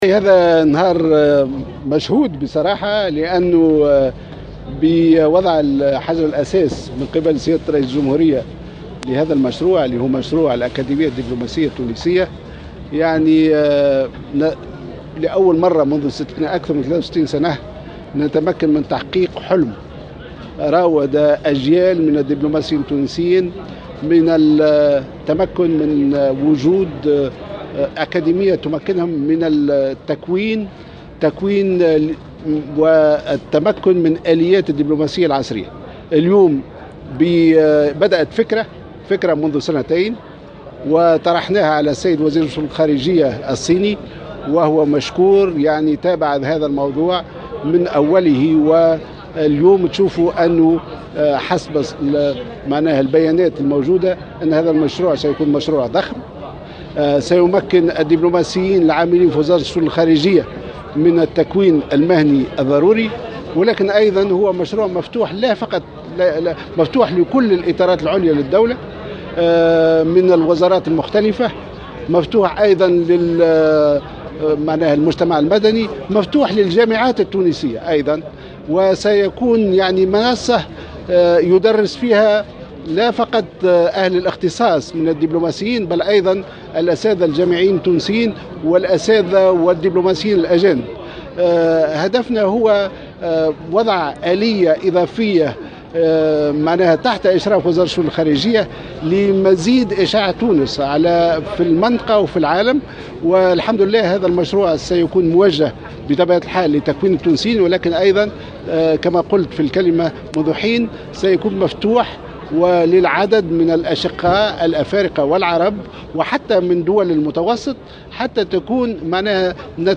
اعتبر وزير الخارجية خميس الجهيناوي في تصريح لمراسلة الجوهرة "اف ام" اليوم الجمعة على هامش وضع حجر الأساس لمشروع الأكاديمية السياسية من قبل رئيس الجمهورية أن هذا اليوم هو يوم مشهود لأنه حلم تحقق منذ أكثر من 60 عاما.